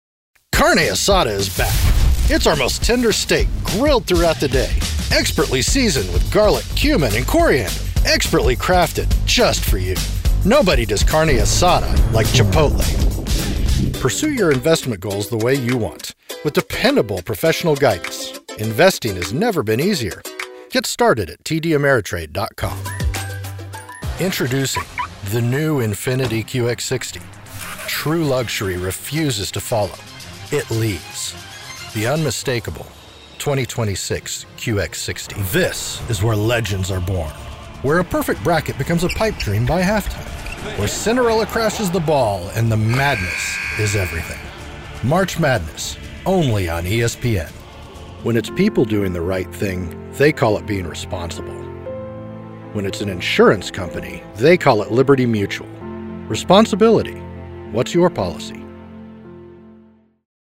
Never any Artificial Voices used, unlike other sites.
Foreign & British Male Voice Over Artists & Actors
Adult (30-50) | Older Sound (50+)
Radio & TV Commercial Voice